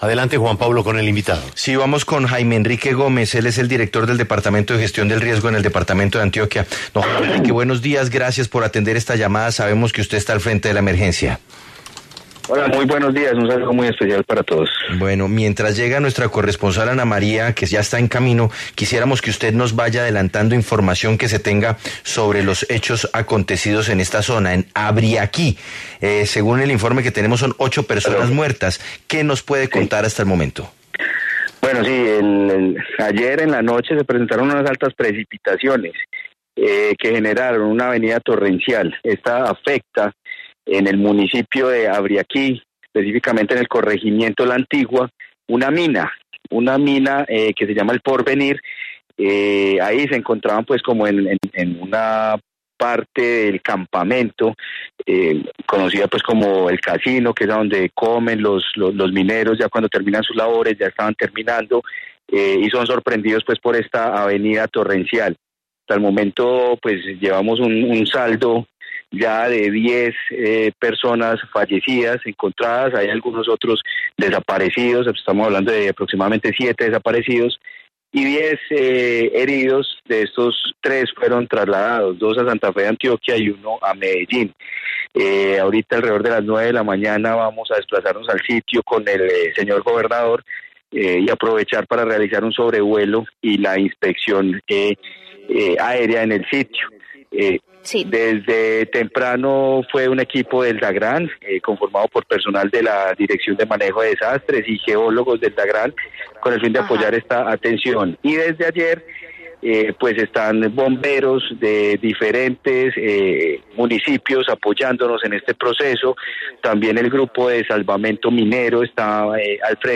En entrevista con La W, Jaime Enrique Gómez, director del Departamento de Gestión de Riesgo de Antioquia, mencionó que “ayer en la noche se presentaron altas precipitaciones que generaron una avenida torrencial en una mina (…) hasta el momento llevamos un saldo de 10 personas encontradas muertas, estamos hablando de 7 desaparecidos y 10 heridos”.